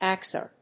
エー‐シーアールエス